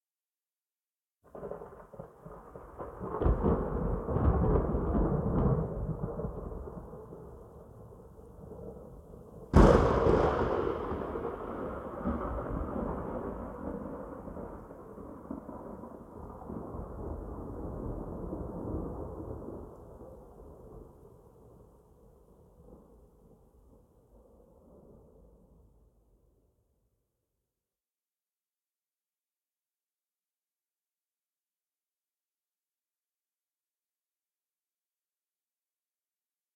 AS-SFX-Thunder 4.ogg